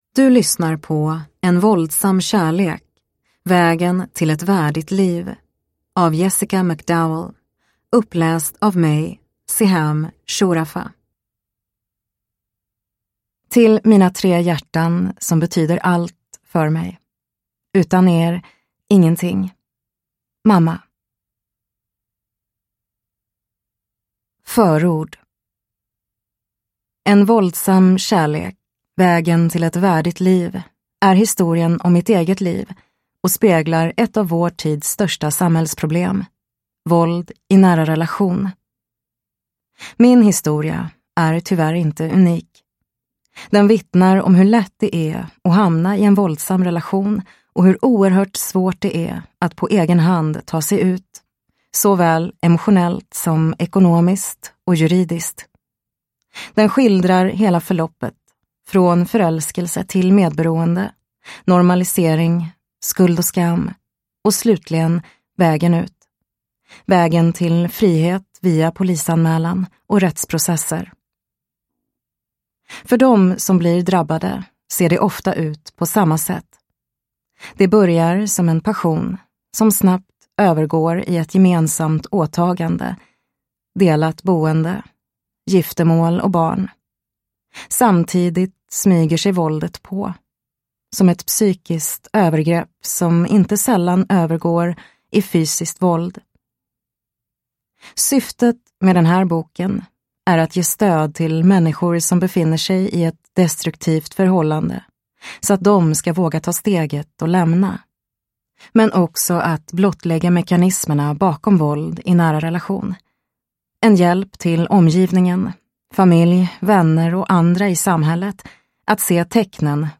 En våldsam kärlek : vägen till ett värdigt liv – Ljudbok – Laddas ner